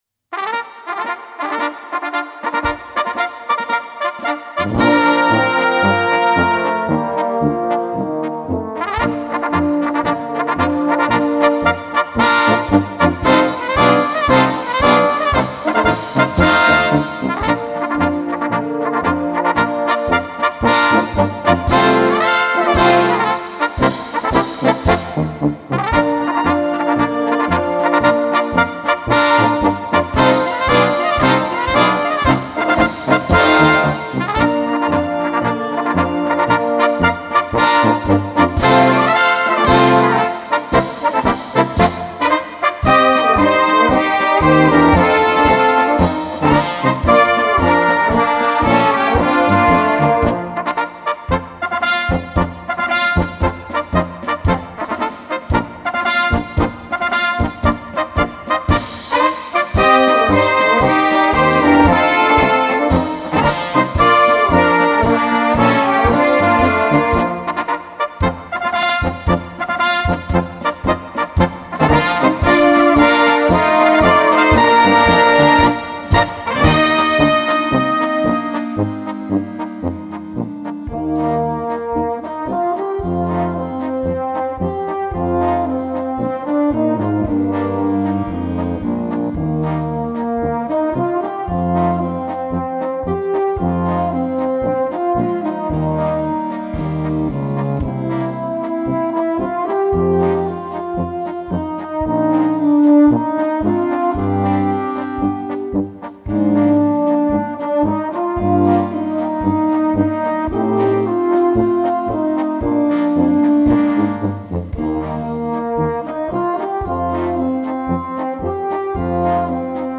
Märsche für Blasmusik